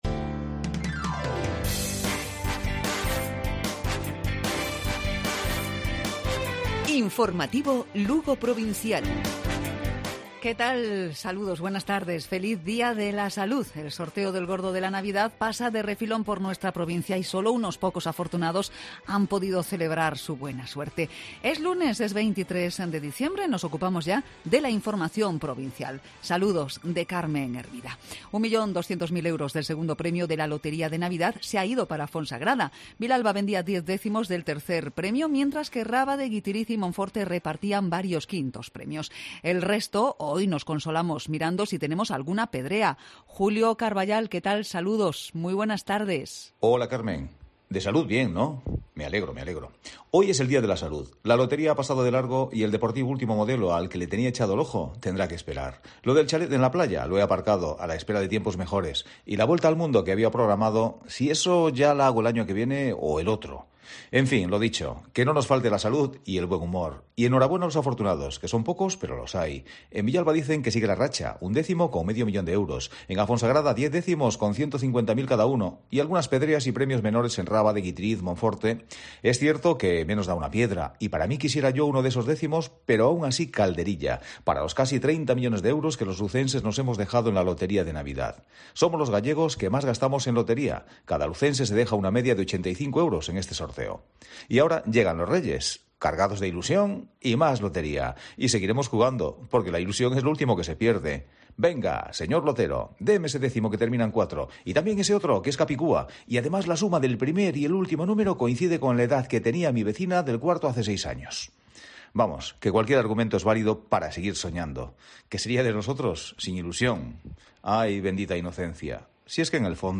Informativo Provincial Cope. Lunes, 23 de diciembre. 12:50-13:00 horas